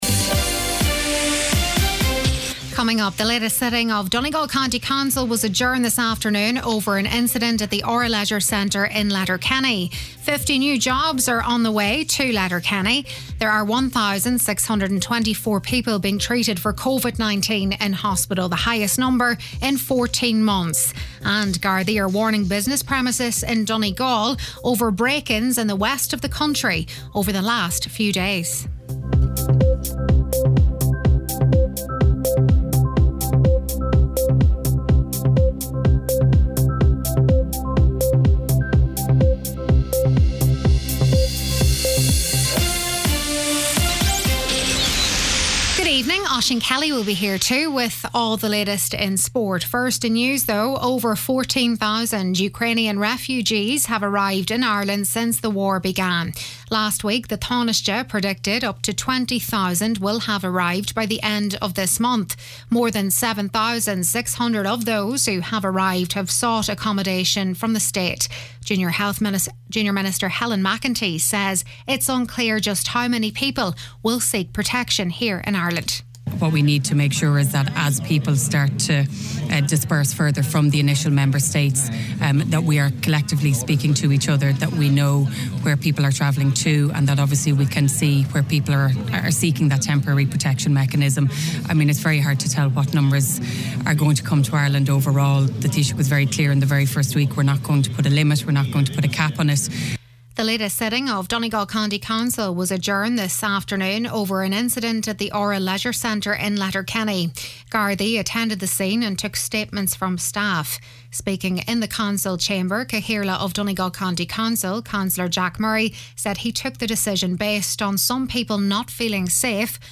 Listen back to main evening news, sport & obituaries